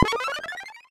1up_b.wav